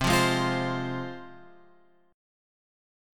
Csus4#5 chord {8 8 6 x 6 8} chord